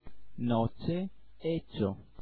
ch c di ciliegia, ma pronunciata con la lingua che tocca i denti superiori (